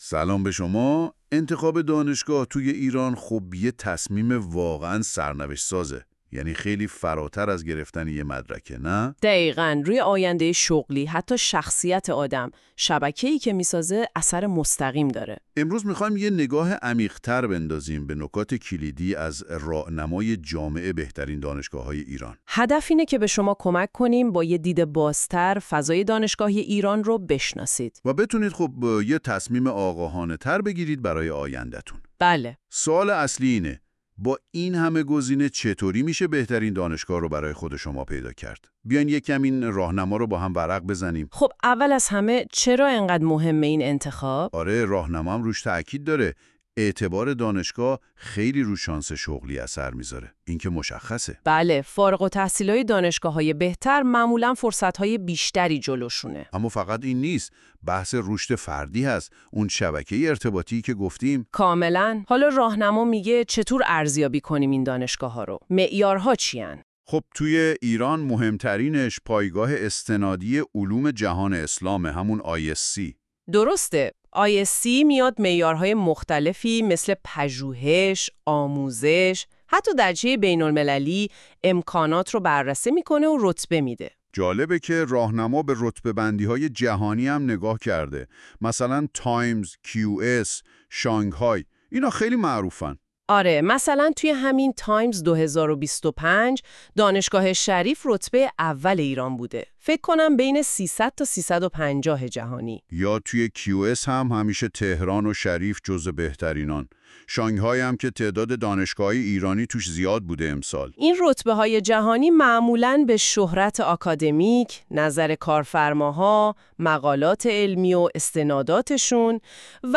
🎧 خلاصه صوتی بهترین دانشگاه های ایران
این خلاصه صوتی به صورت پادکست و توسط هوش مصنوعی تولید شده است.